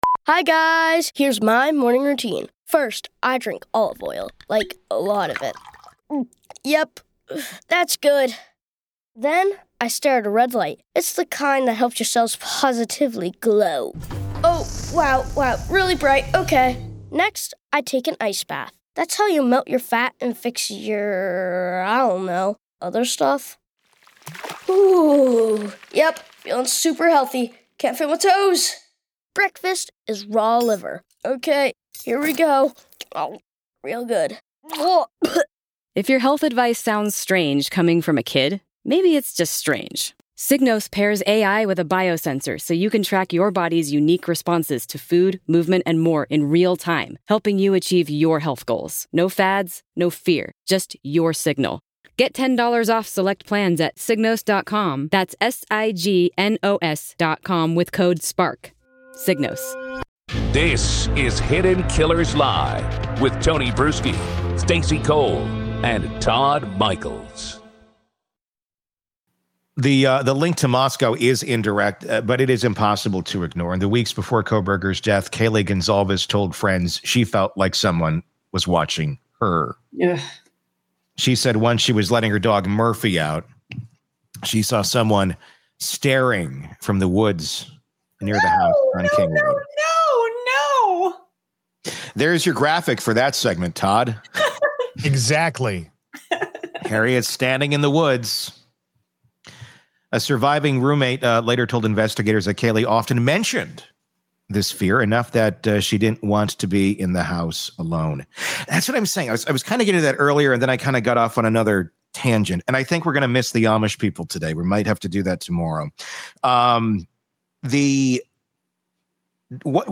The result is a gripping combination of live banter and expert analysis, tying together narcissism, numerology, forensic details, and FBI insight into what might still be hidden out there.